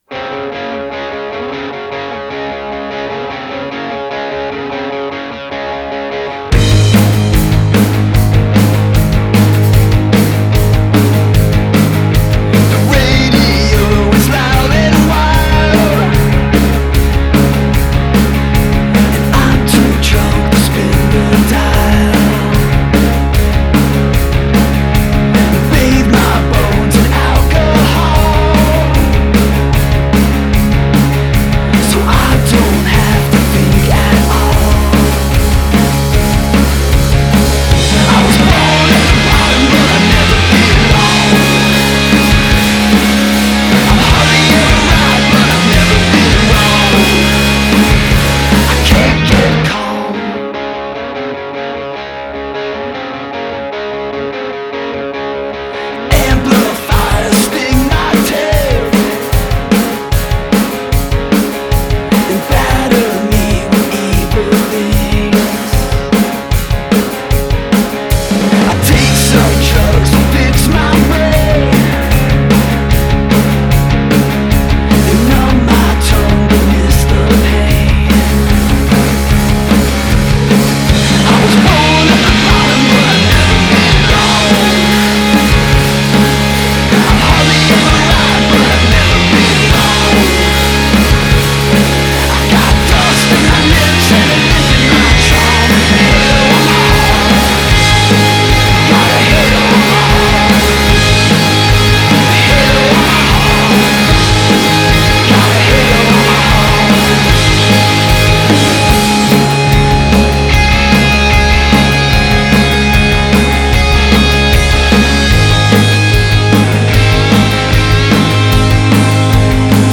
punchy guitar rock